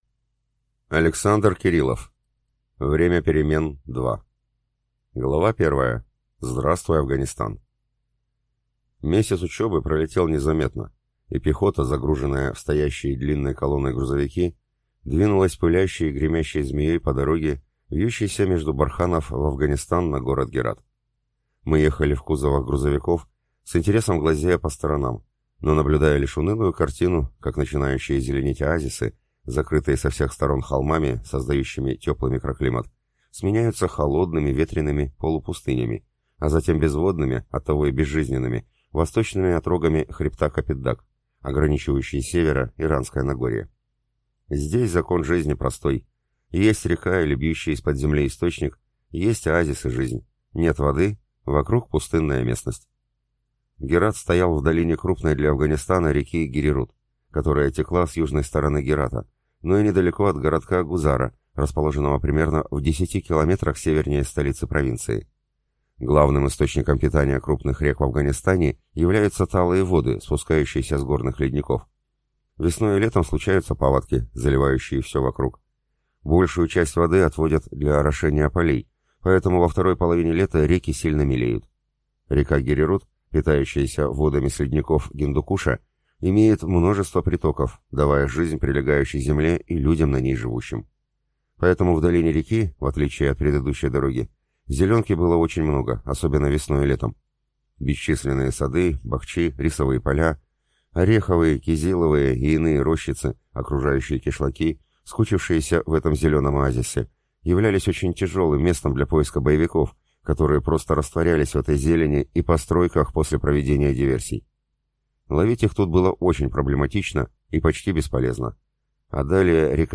Аудиокнига Время перемен 2 | Библиотека аудиокниг